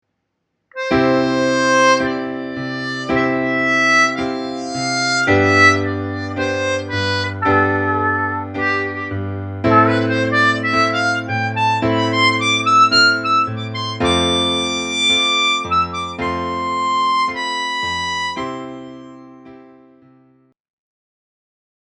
Расширяем возможности, чтобы разнообразить импровизацию | САМОУЧИТЕЛЬ ИГРЫ НА ГУБНОЙ ГАРМОШКЕ
• начать в импровизации использовать ноты разной длительности